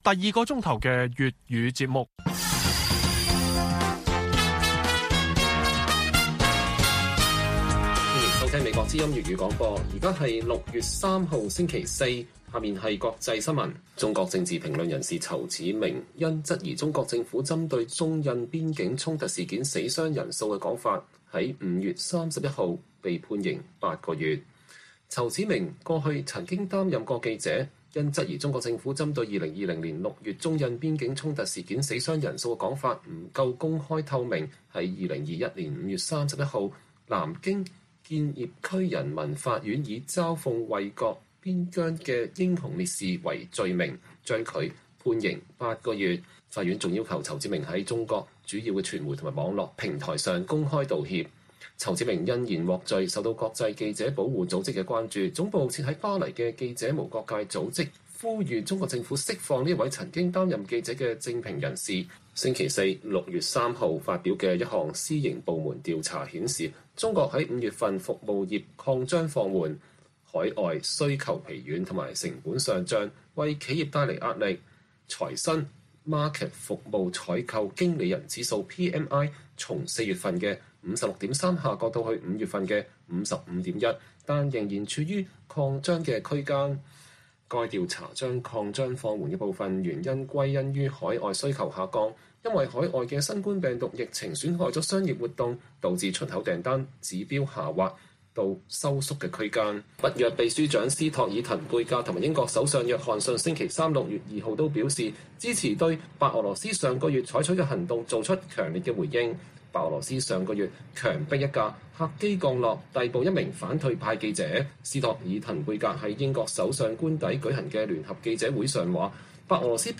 粵語新聞 晚上10-11點: 台灣指責中國以疫苗謀取政治利益